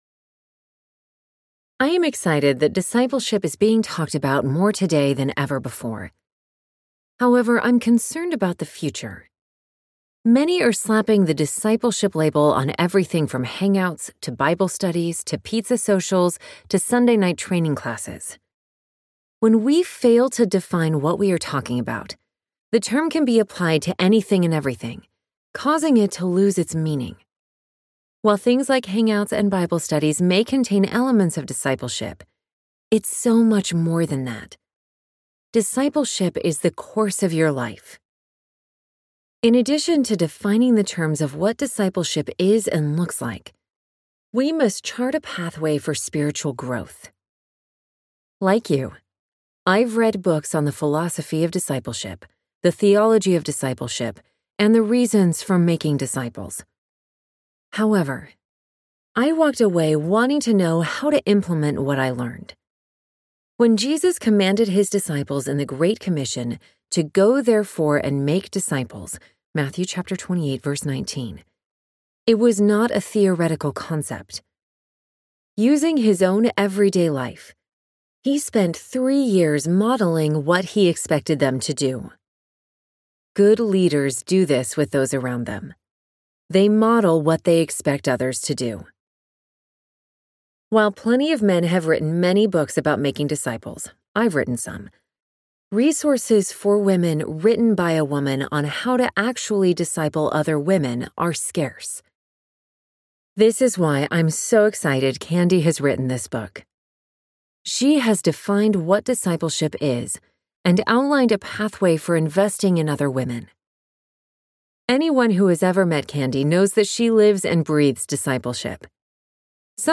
Disciple Her Audiobook
Narrator
5.68 Hrs. – Unabridged